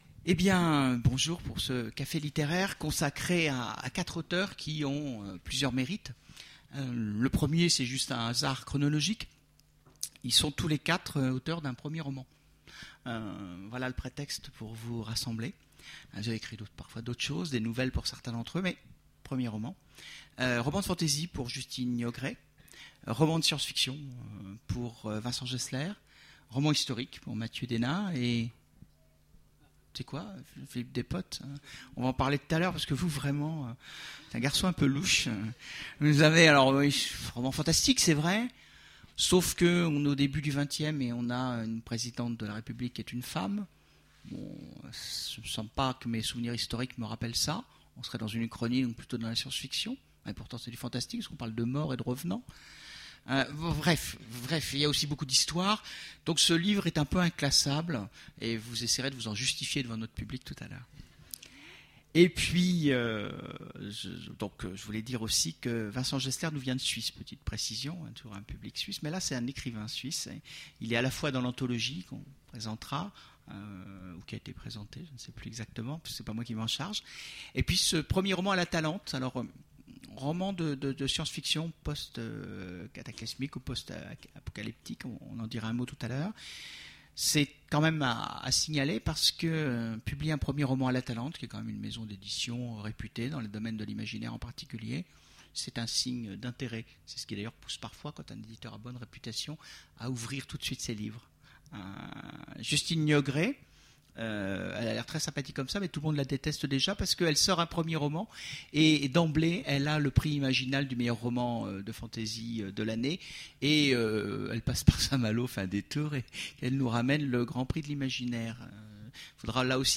Voici l'enregistrement de la conférence Quand un inconnu nous surprend? aux Imaginales 2010